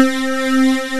FUNK C5.wav